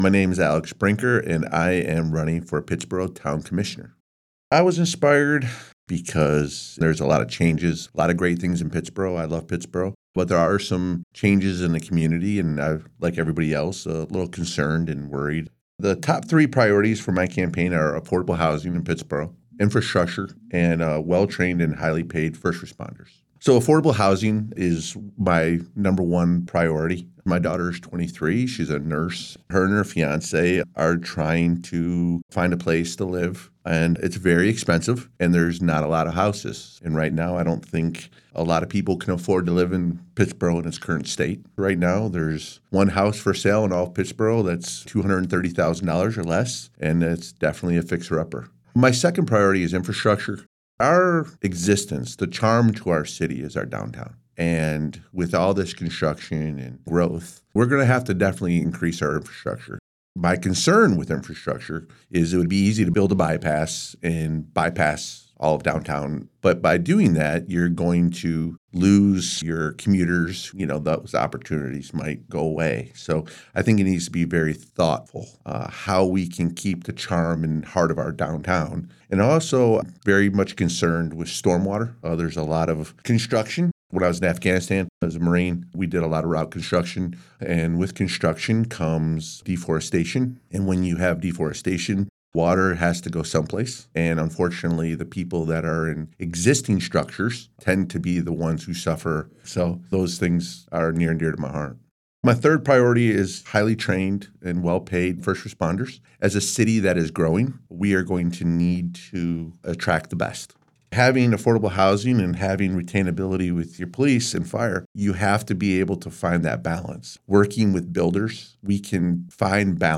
97.9 The Hill spoke with candidates, asking these questions that are reflected in the recorded responses: